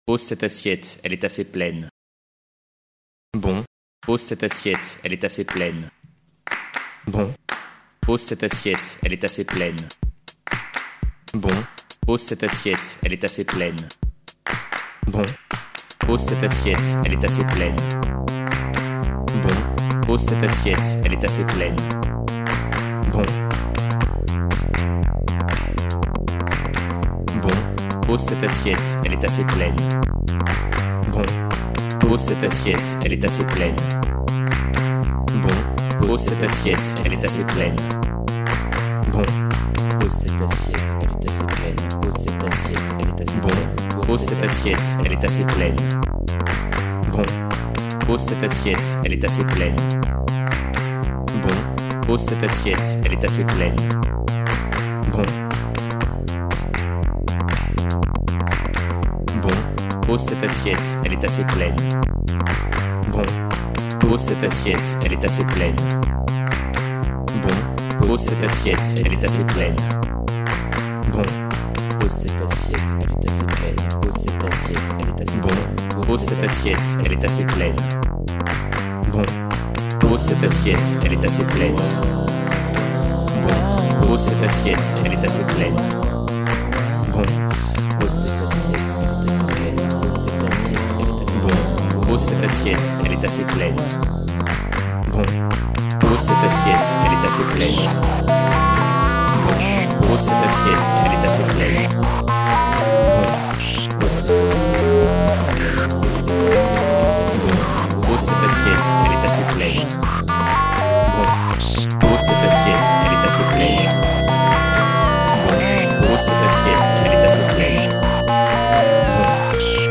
Un appartement vide que l'on quitte, qui r�sonne, dont on teste la reverb en tapant dans les mains.
Une exclamation de r�signation et de d�part lorsqu'une soir�e s'enlise. Un microKORG. Con�u et enregistr� une heure avant de partir pour l'Open Mic.